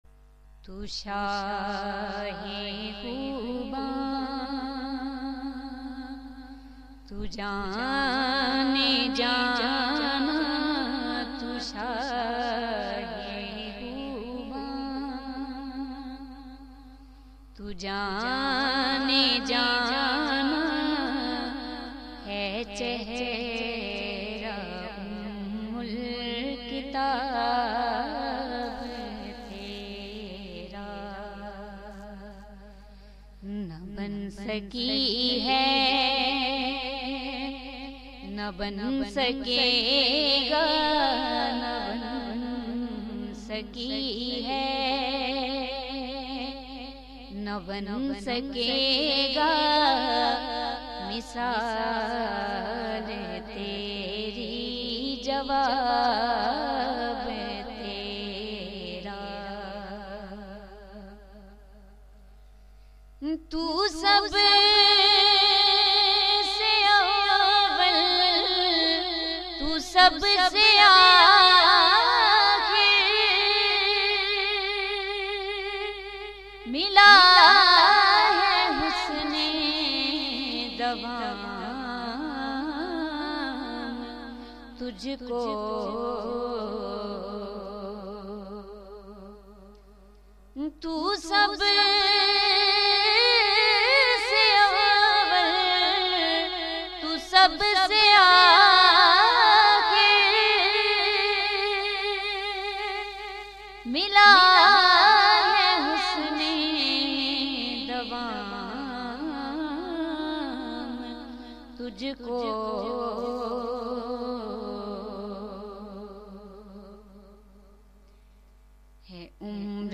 naat
in a Heart-Touching Voice